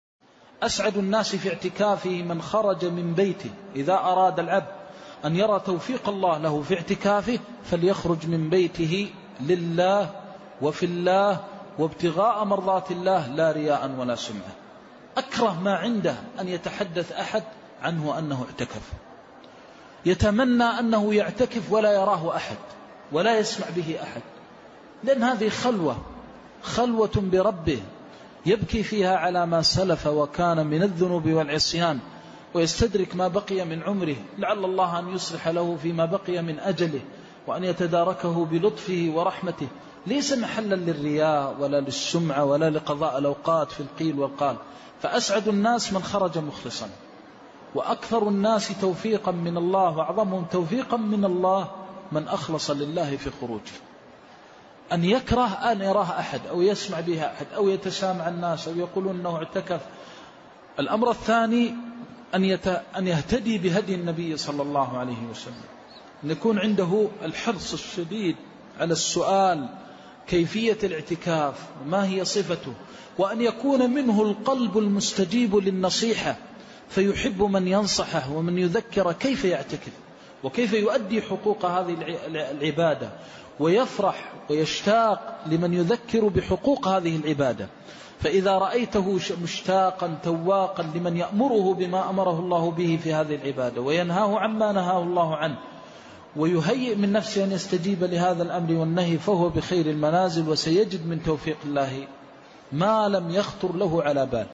مقطع قصير: أسعد الناس في اعتكافه - محمد بن محمد المختار الشنقيطي (صوت - جودة عالية